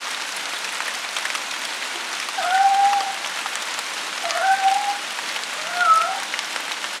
Un livre sonore pour écouter  les animaux de la nuit : le hibou, le hérisson, le rossignol, les grenouilles, le miaulement et le ronronnement du chat… et s’endormir.
Les sons vont au-delà des simples cris des animaux. Ils restituent l’univers de la nuit.